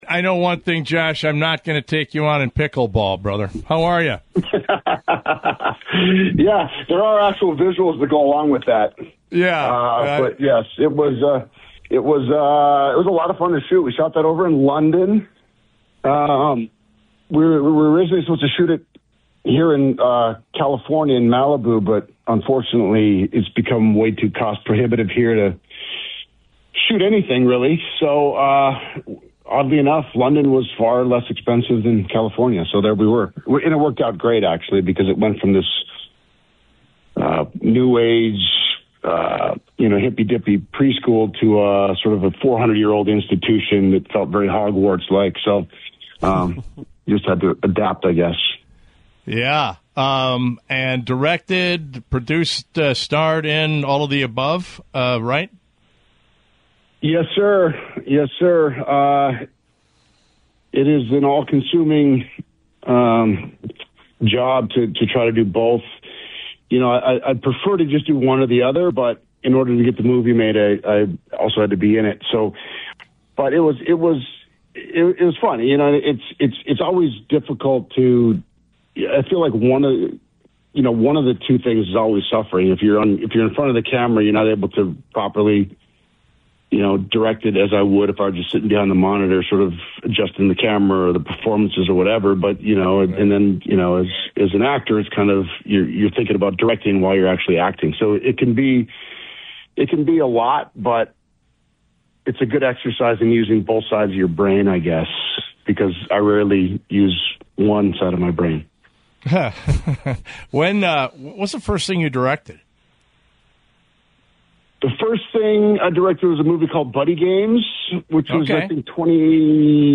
“When I first heard that they were going to make a modern version of Transformers, I thought it was going to be a terrible idea. And then they showed me the artwork they had and plans for this new imagined idea. Then I understood it was going to be cool,” Duhamel said on “What’s On Your Mind?” on The Flag.